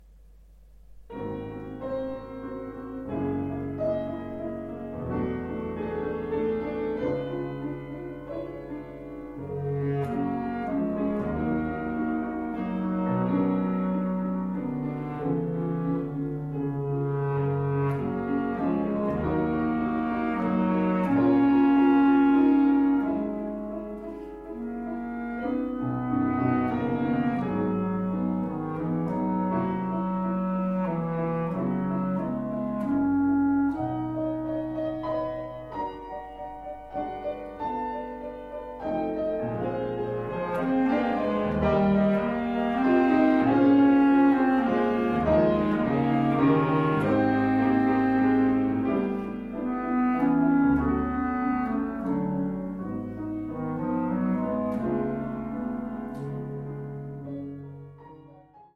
Pour clarinette basse et piano